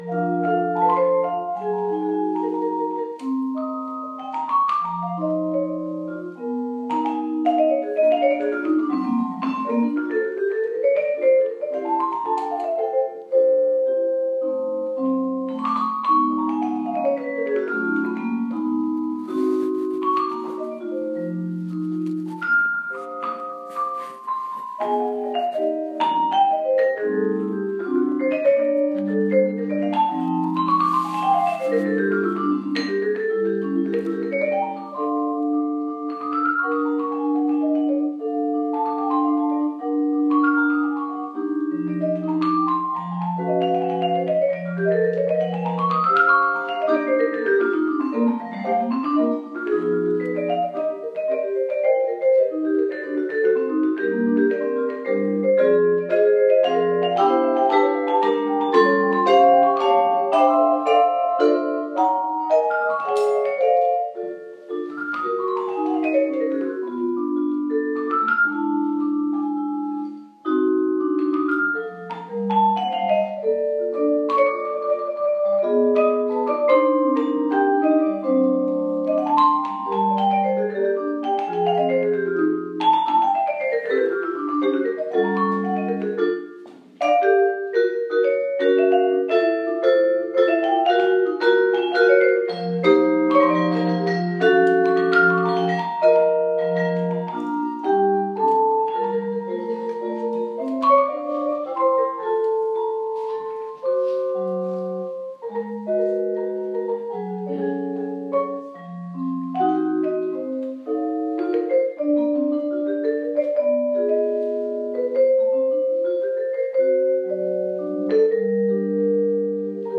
concert-jazz-1
concert-jazz-1.m4a